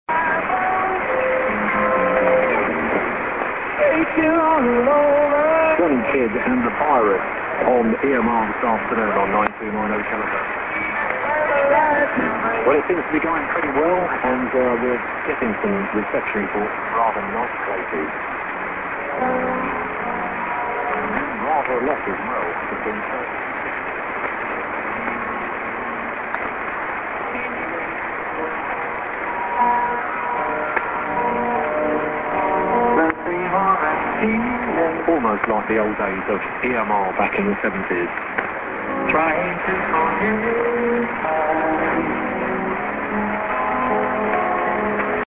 music->ID@00'08"->music